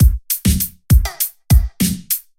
LP_Posse Up_100bpm.ogg